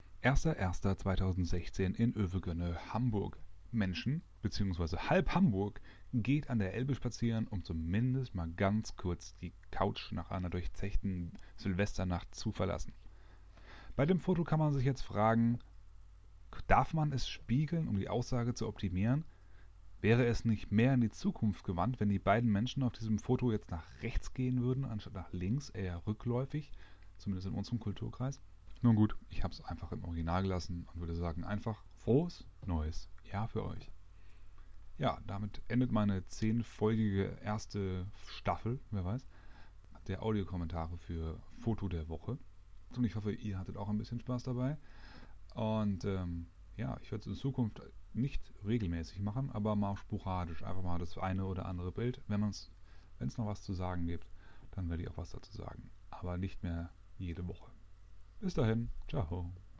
Mein Audikommentar: Audiokommentar nächster| vorheriger ...